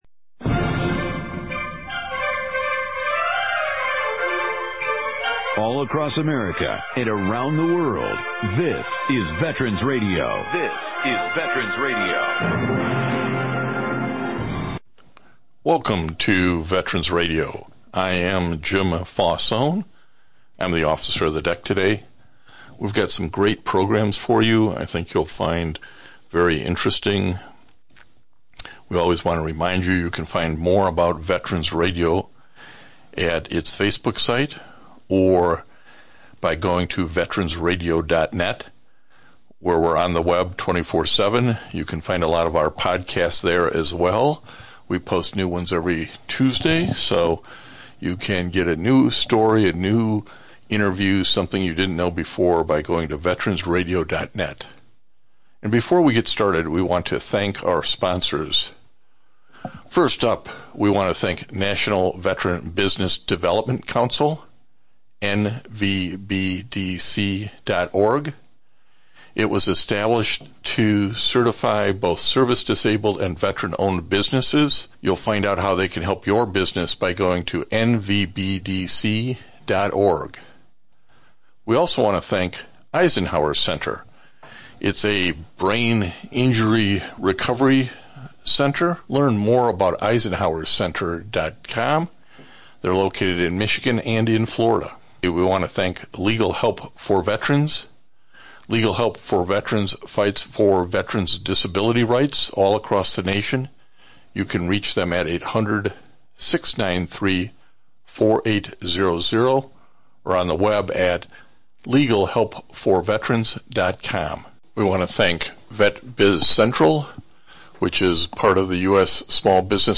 one hour radio broadcast